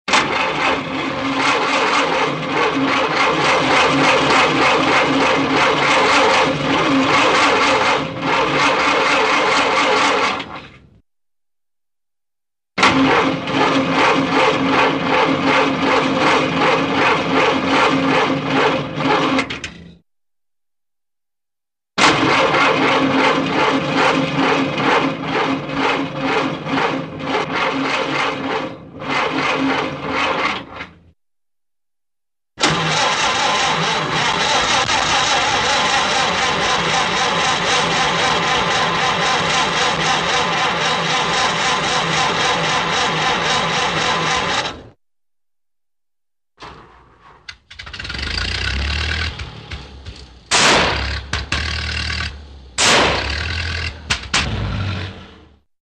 Звуки стартера автомобиля
Безуспешные попытки завести машину, стартер крутят несколько раз